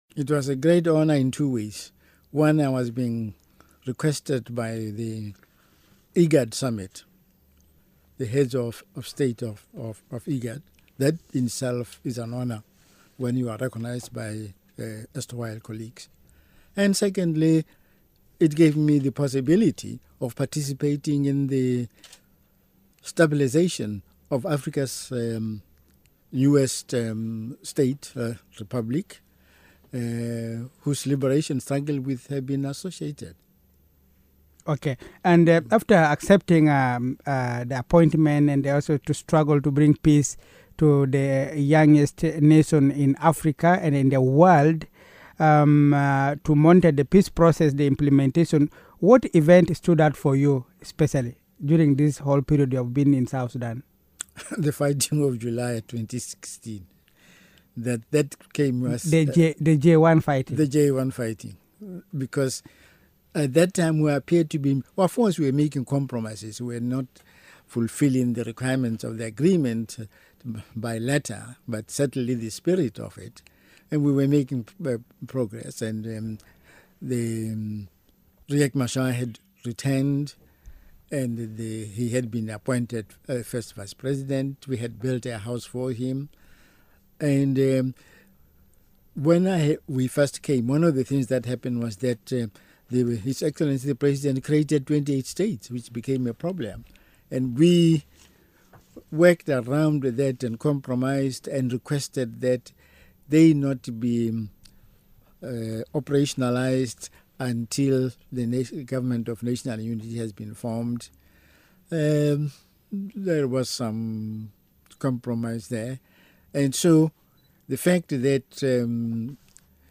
Exit Interview with H.E Festus Mogae, Chairman of JMEC